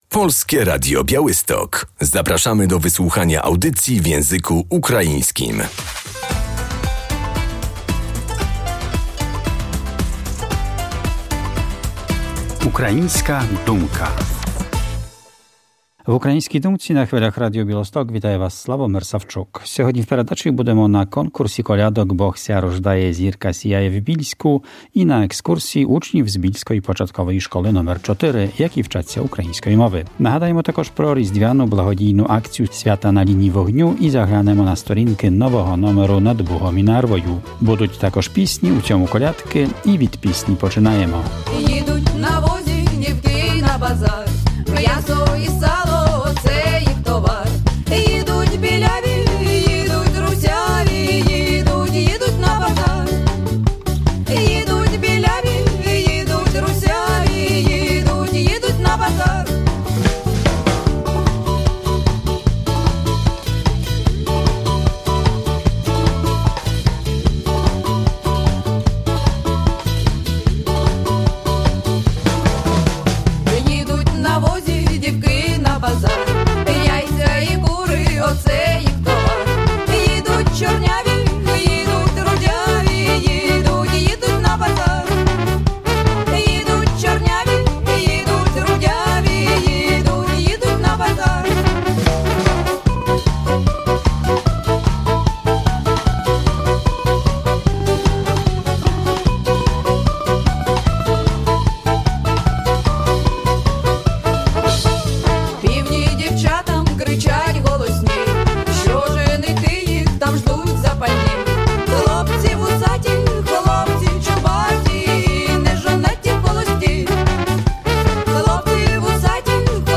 W Bielskim Domu Kultury odbył się konkurs kolędniczy ,,Bóg się rodzi, gwiazda wschodzi”.